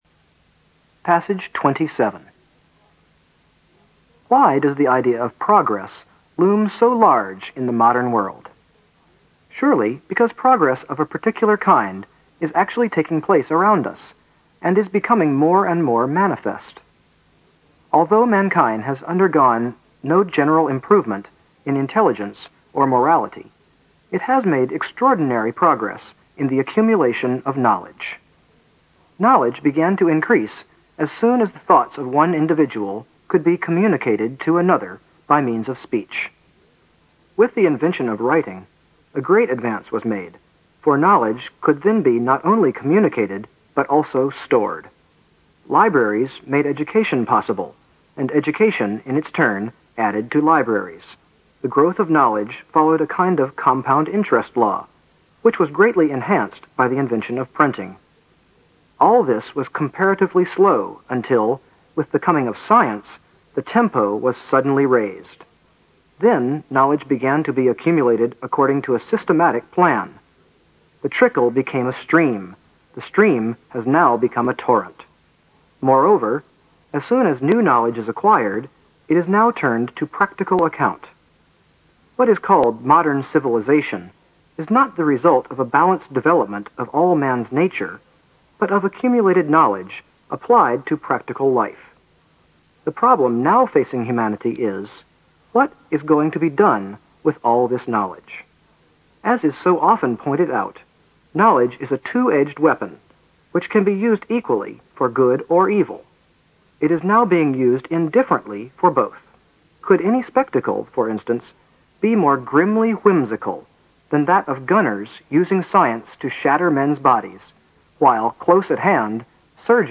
新概念英语85年上外美音版第四册 第27课 听力文件下载—在线英语听力室